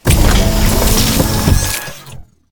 droidic sounds